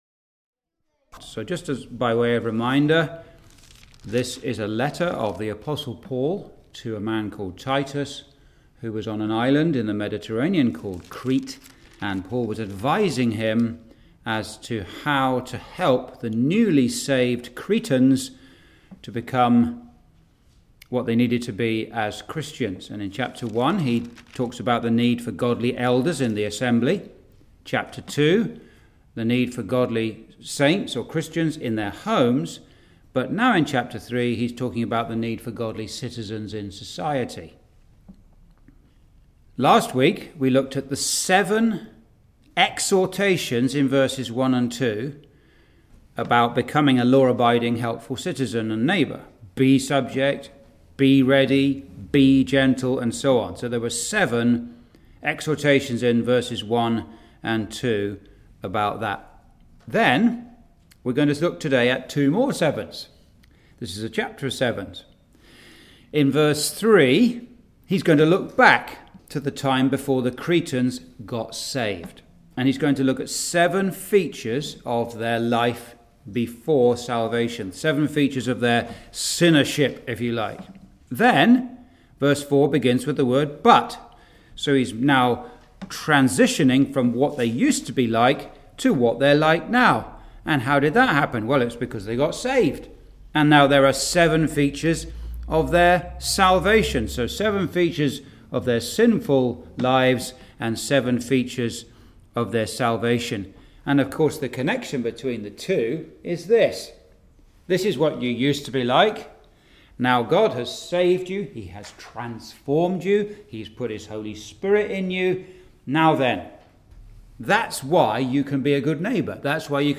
Verse by Verse Exposition